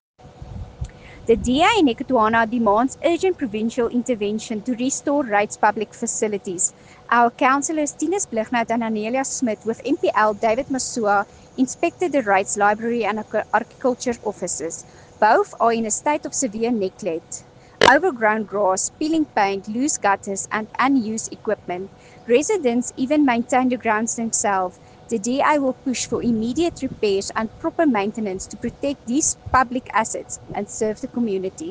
Afrikaans soundbites by Cllr Anelia Smit and